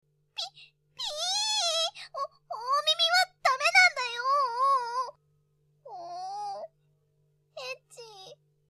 cv：背後(ましろの声出せませｎ/げふごふ)
再生前に、耳鼻科の予約をしておくことをおすすめするんだよ(ぐっ)